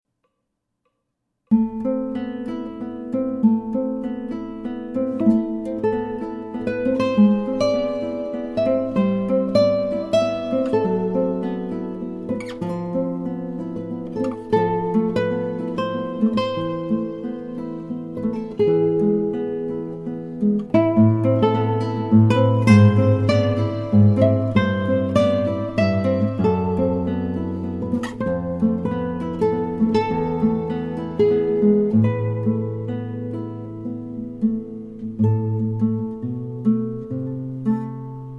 Ton vibrato rend l'expression encore plus prenante.